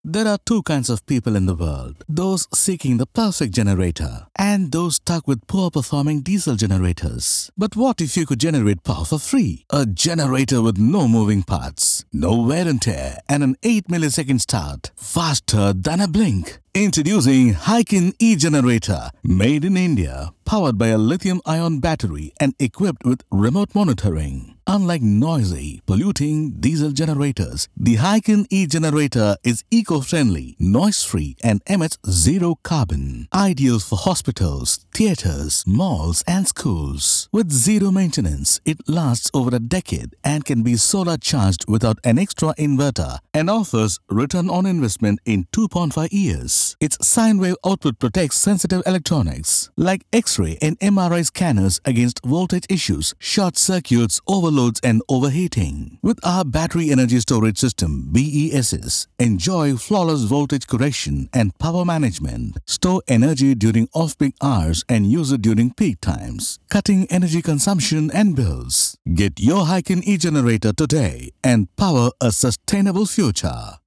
Corporate & Industrial Voice Overs
Adult (30-50)